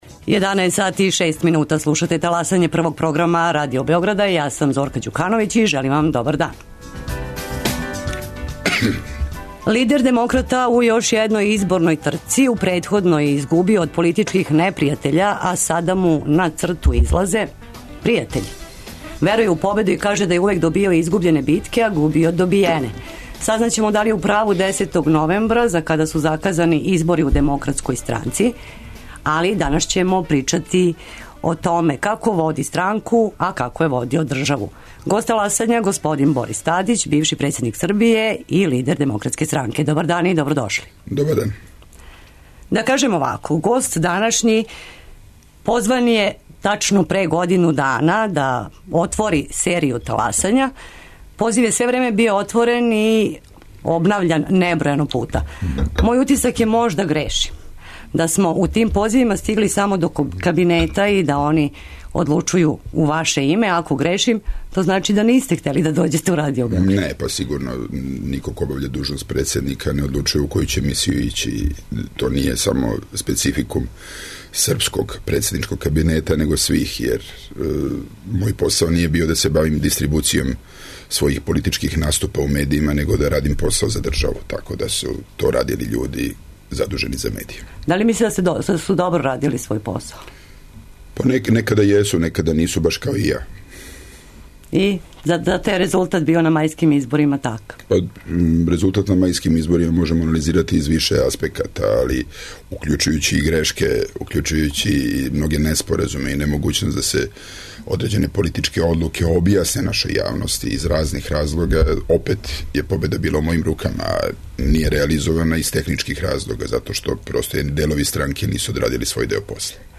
Гост "Таласања" Борис Тадић, бивши председник Србије и председник ДС. Питамо га како је водио странку, а како државу?